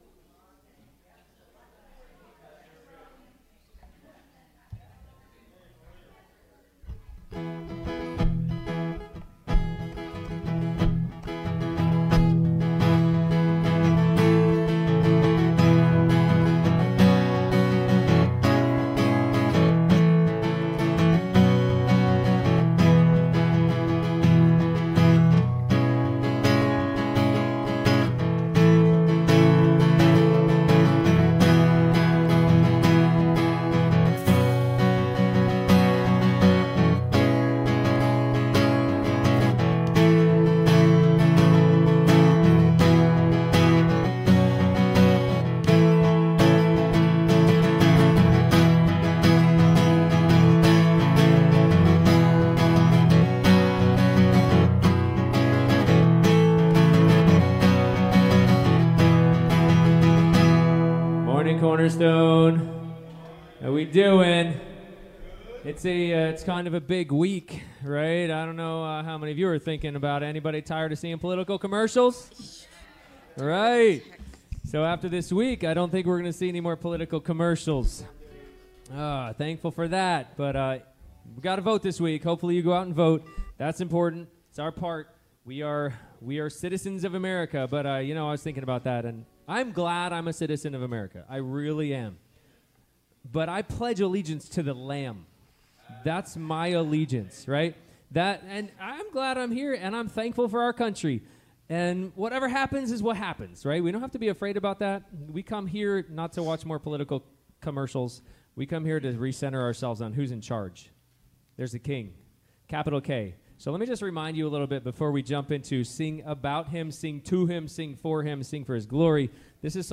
Ephesians 6:5-9 Service Type: Sunday Morning Youversion Event The secret to enjoying your work